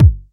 Havoc Kick 15.wav